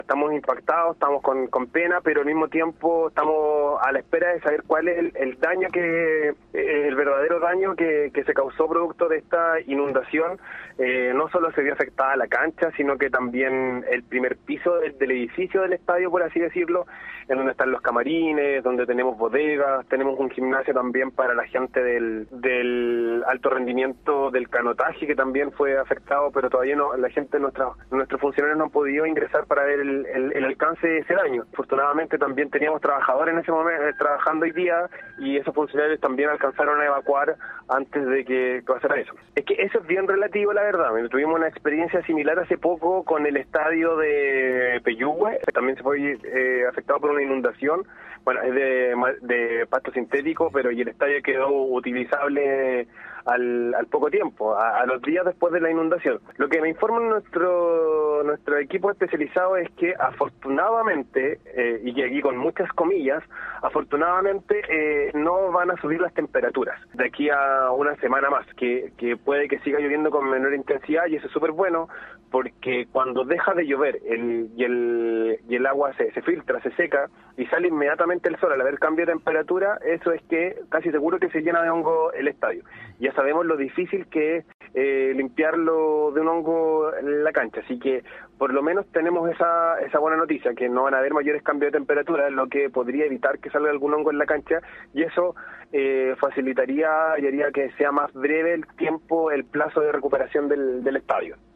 En un primer momento, en diálogo con Gigante Deportivo, el Seremi del Deporte, Iván Sepúlveda, evitó precisar una fecha para volver a contar con el Estadio.
2.4.-IVAN-SEPULVEDA-SEREMI.mp3